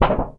metal_tin_impacts_wobble_bend_03.wav